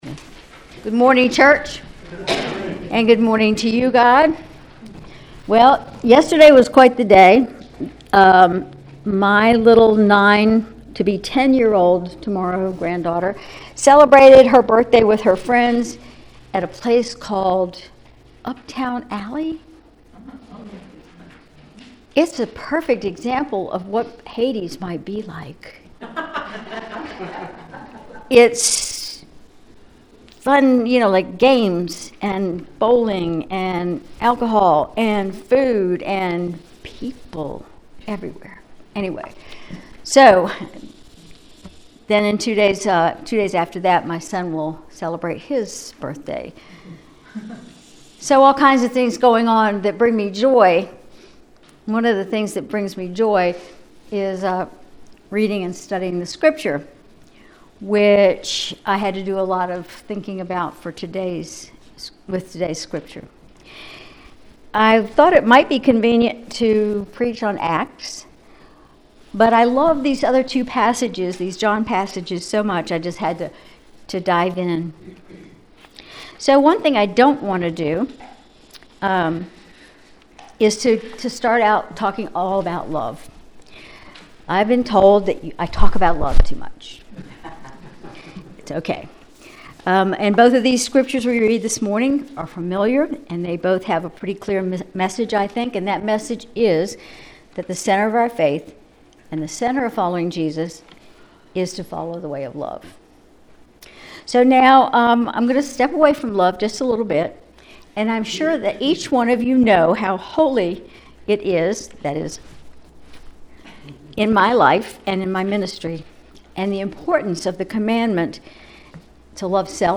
Sermon April 28, 2024